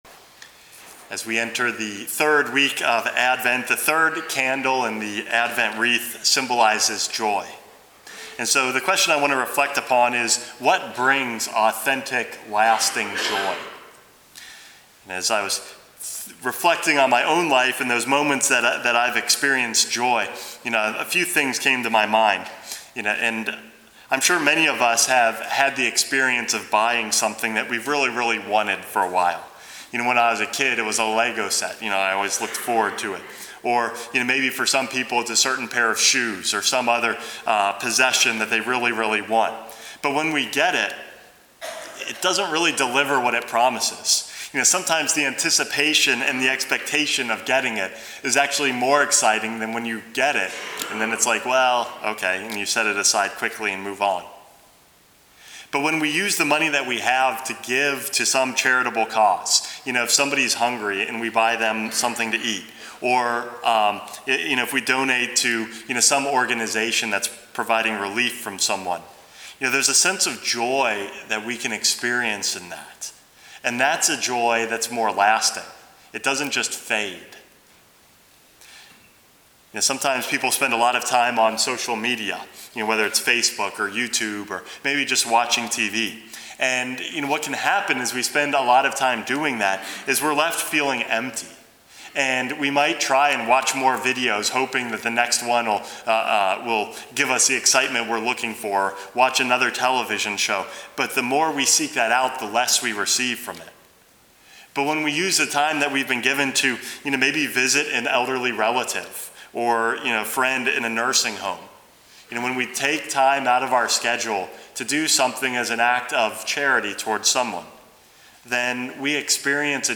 Homily #429 - The Third Candle